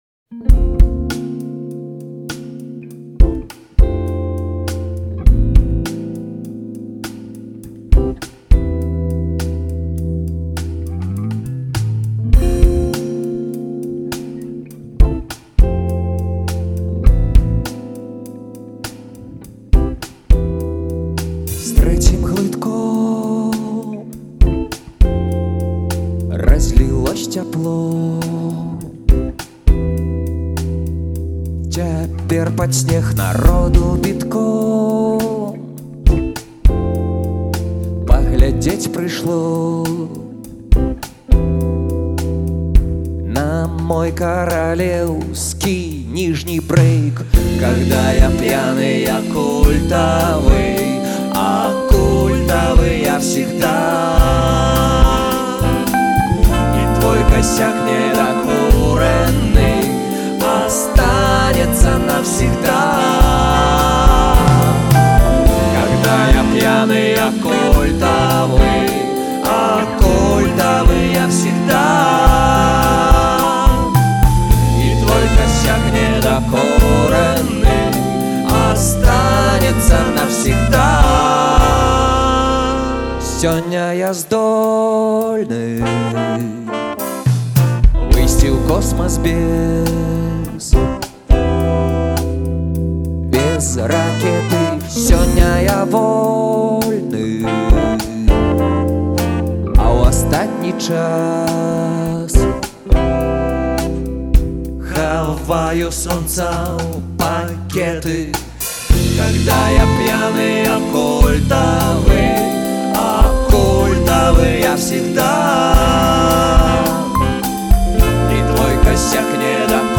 ў джазавай вэрсіі